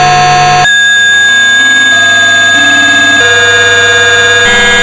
Я не стал брать предложенный преподавателем девайс, а вместо этого одолжил у друга Arduino и сделал на нём пианино, с микрокнопками и собственным ЦАП'ом из резисторов.
Во время написания программы для микроконтроллера было создано много смешных звуков, например эти: